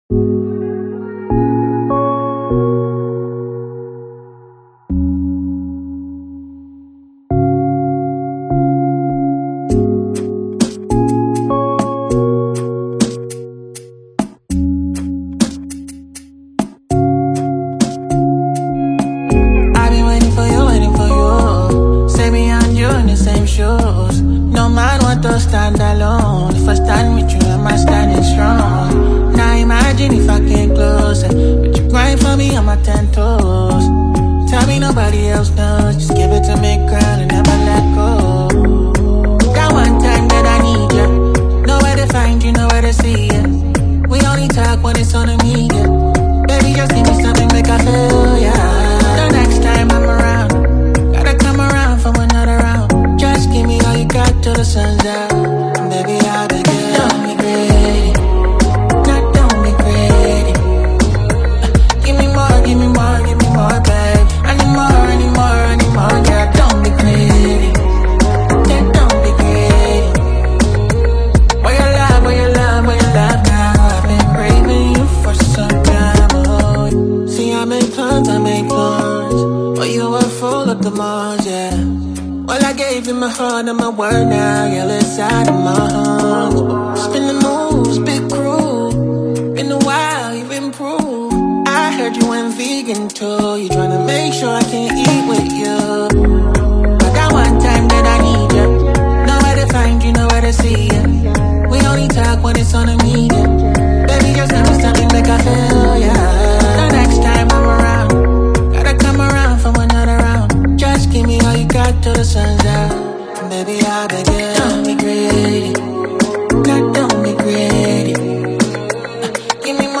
soulful Afro-R&B masterpiece